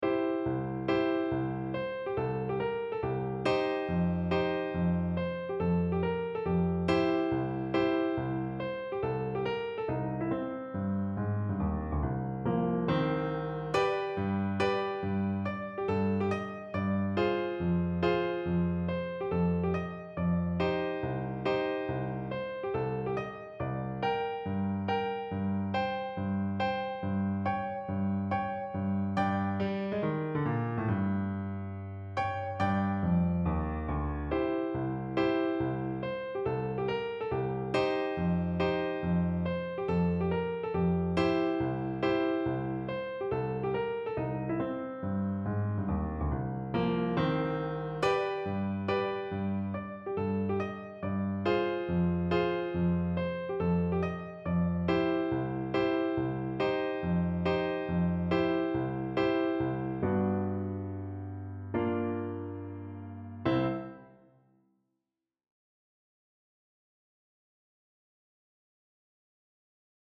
Swinging Halloween Piano Solo
Mysterious Vibes